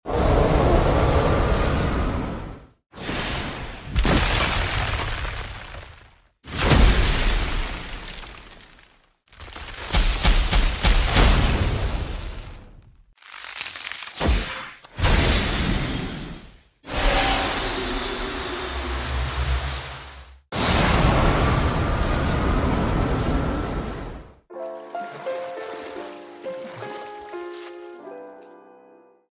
게임 및 영화 <얼음 속성 sound effects free download
공격 스킬 - 스킬 시전 사운드와 함께 얼음 질감이 중요 - 임팩트 있는 저음과 얼음의 날카로운 질감을 표현하기 위해 고음을 조절 얼음 버프 스킬 - 얼음 마법과 함께 버프 느낌의 마법 사운드를 표현.